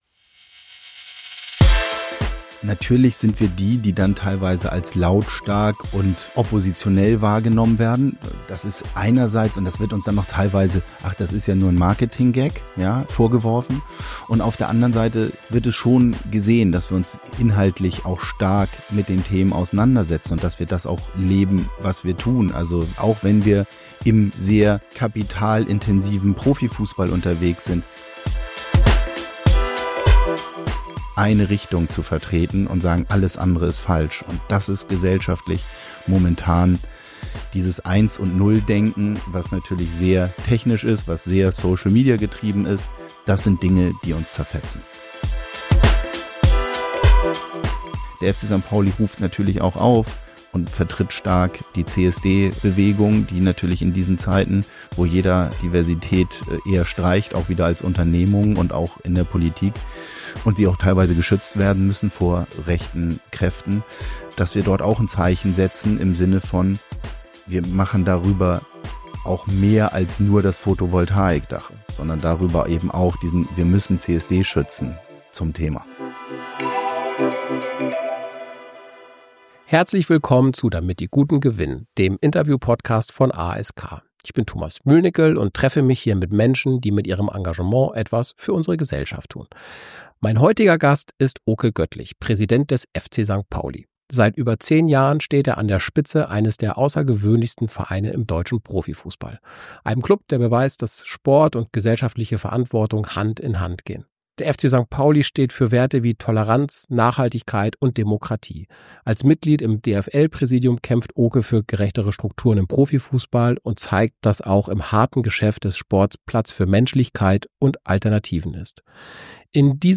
Oke erklärt, warum Vereine heute mehr denn je Verantwortung tragen, welche Rolle Fans dabei spielen – und wie ein Klub wie der FC St. Pauli politisch, wirtschaftlich und gesellschaftlich Position bezieht, ohne sich vereinnahmen zu lassen. Ein Gespräch über sportliche Leidenschaft, politische Klarheit und die Kraft der Gemeinschaft.